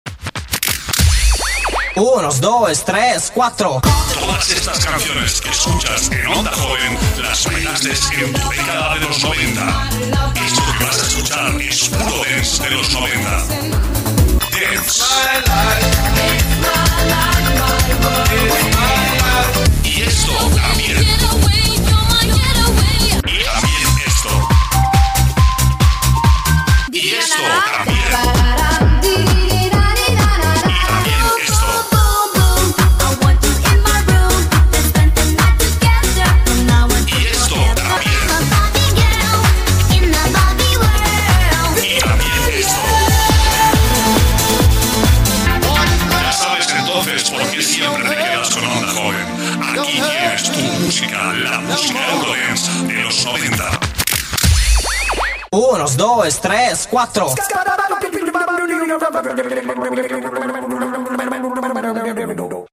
Promoció de l'emissora